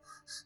groans_06.mp3